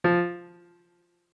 MIDI-Synthesizer/Project/Piano/33.ogg at 51c16a17ac42a0203ee77c8c68e83996ce3f6132